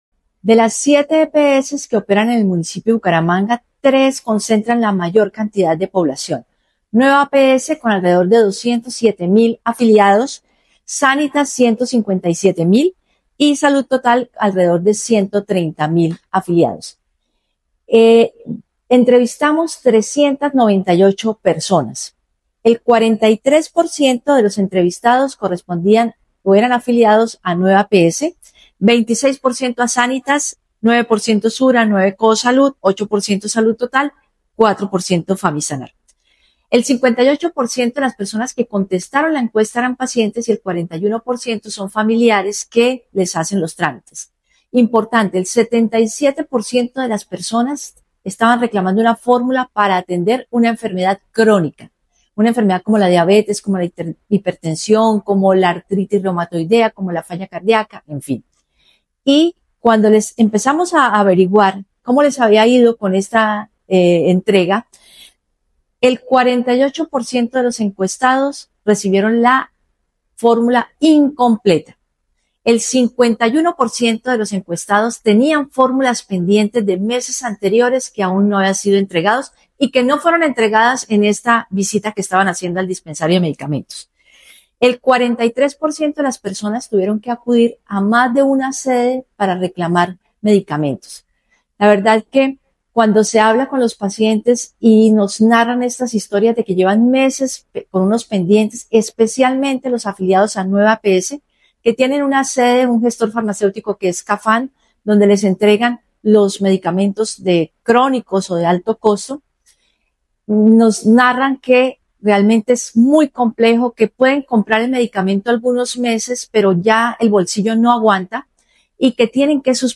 Claudia Amaya, secretaria de Salud de Bucaramanga